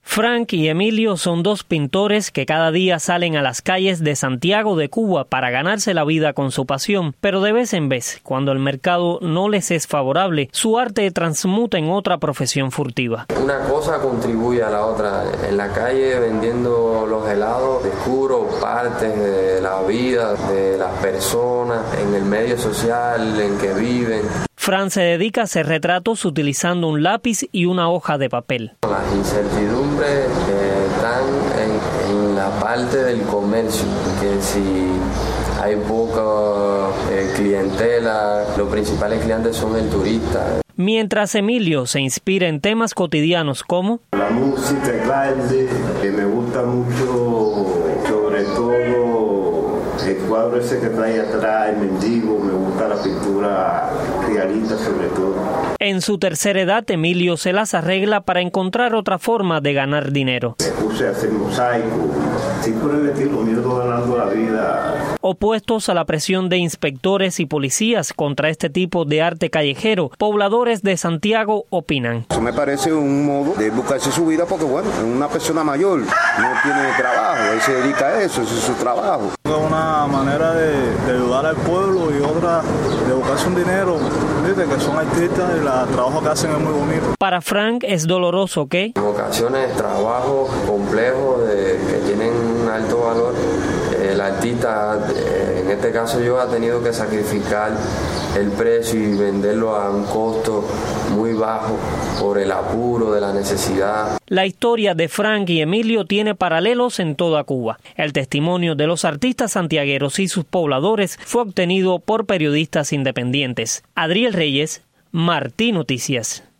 Escuchemos el reporte...